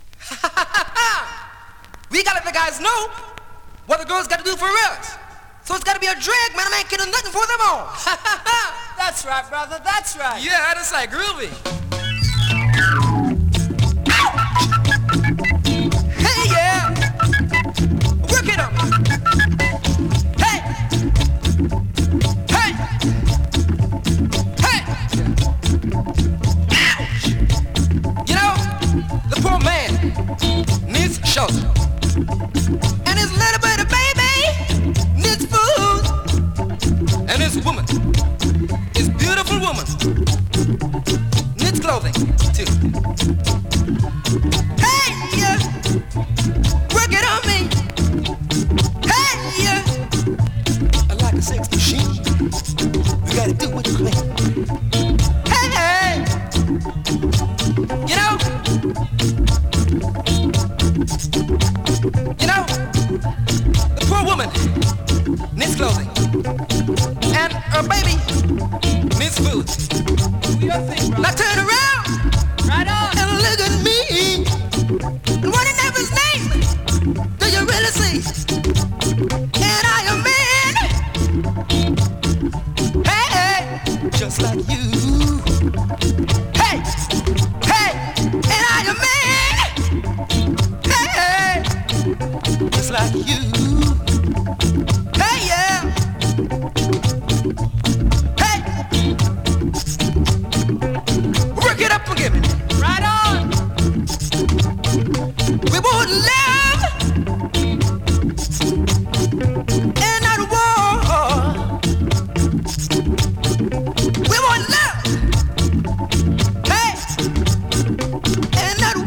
2025!! NEW IN!SKA〜REGGAE
スリキズ、ノイズ比較的少なめで